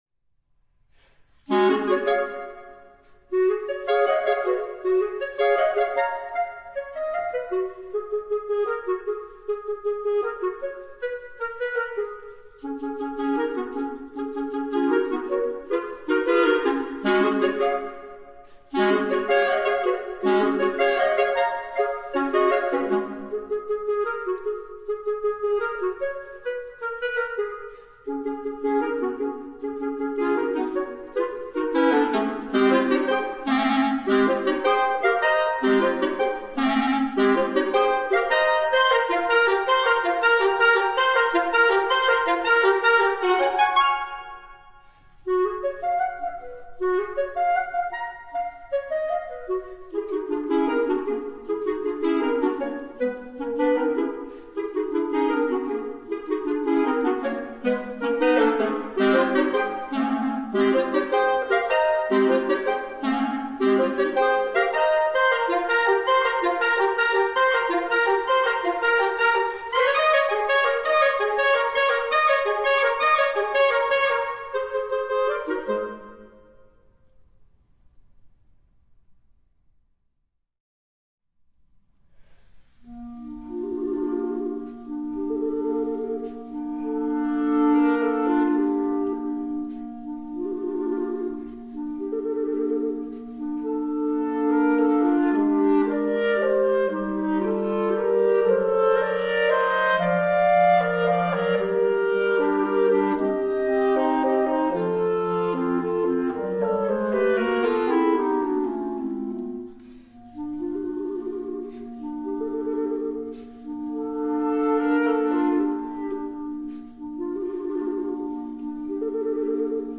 B♭ Clarinet 1 B♭ Clarinet 2 B♭ Clarinet 3
单簧管三重奏